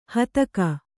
♪ hataka